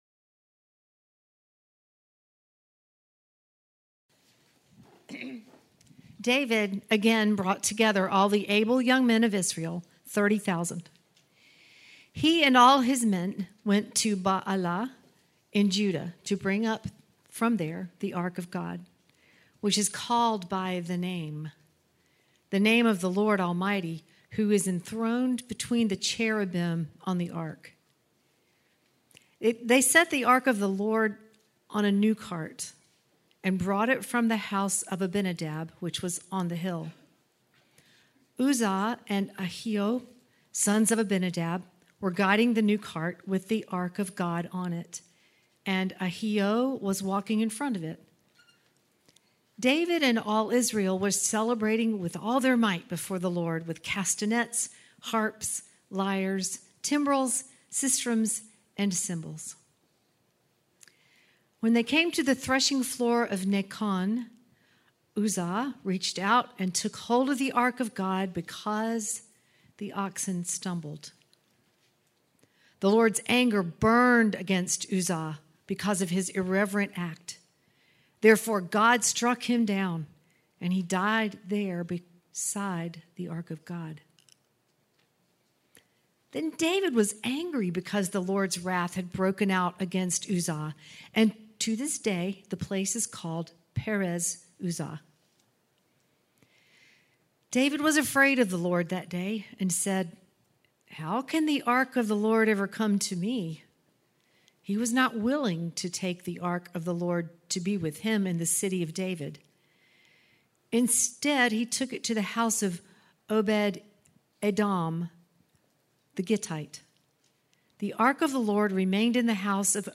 Weekly teaching audio brought to you by Mosaic Church in Evans, GA
Preaching and Teaching Audio from Mosaic Church